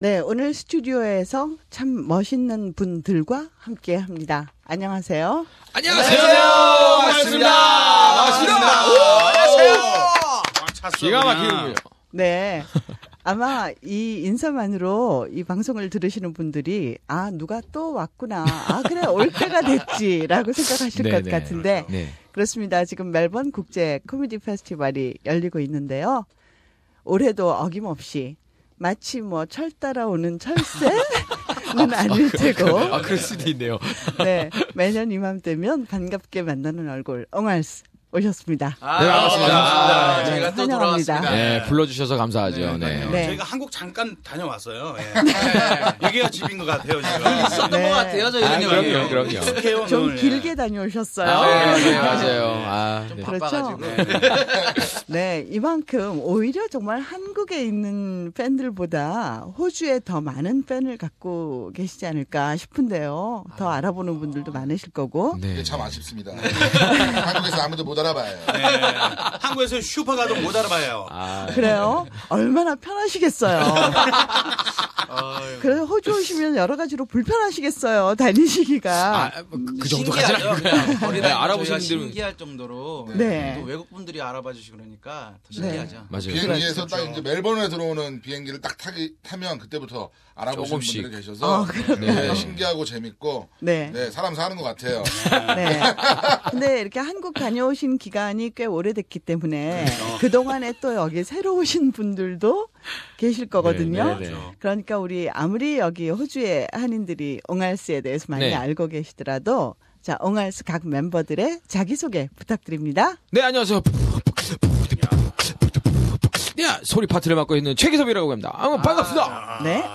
SBS Korean program meet them in Melbourne Studio and listen how they start challenge to the high wall in World Comedy.